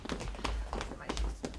sfx_walk.ogg